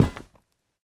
step1.ogg